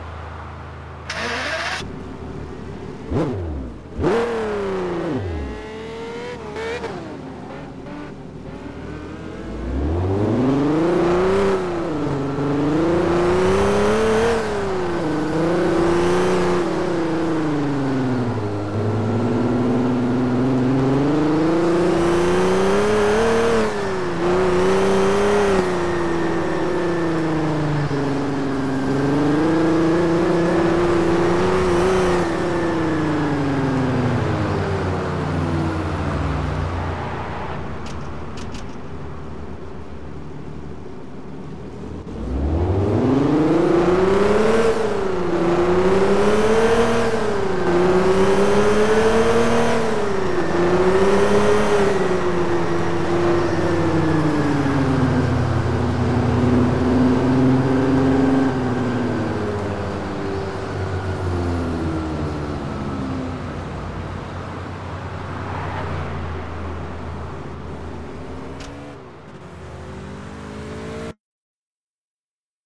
- Audi A8 [D4]
- Mercedes-Benz S 600 Coupé AMG [C140] - Audi Q7 V12 TDI